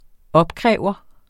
Udtale [ ˈʌbˌkʁεˀvʌ ]